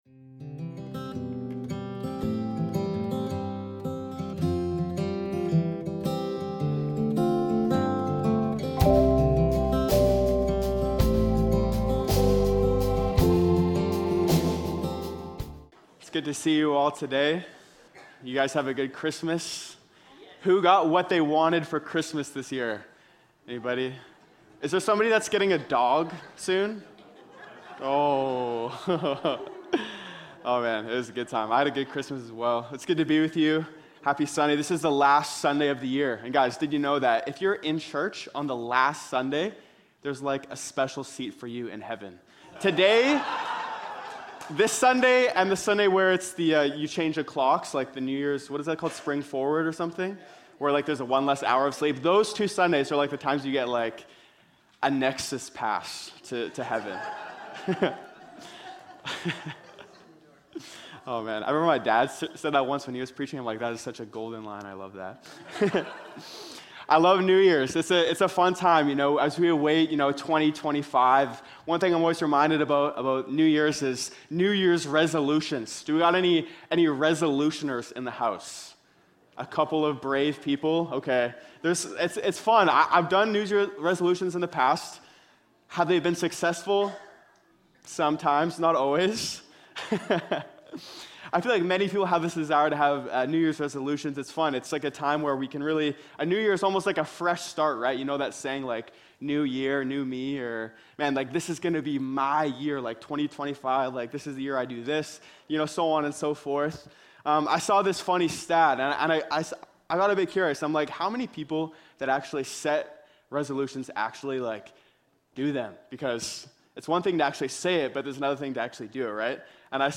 stand alone message